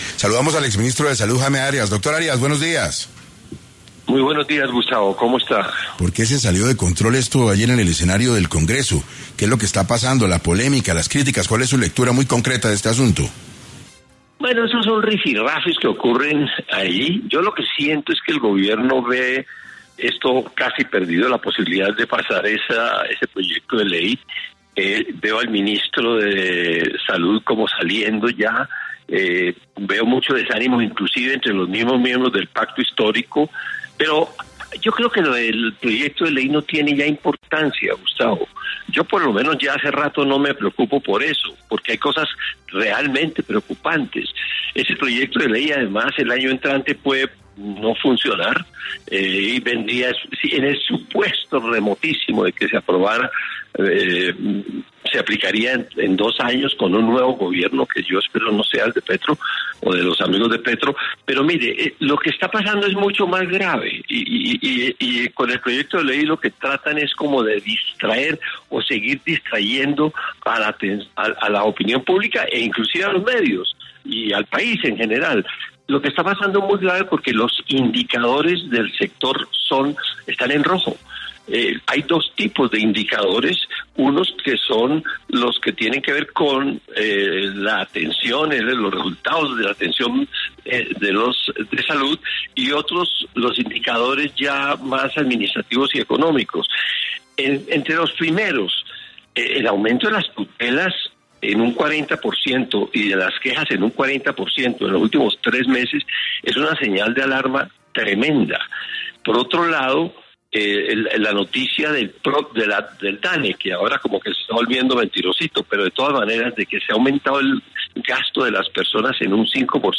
En Caracol Radio estuvo Jaime Arias, exministro de Salud hablando sobre la reforma.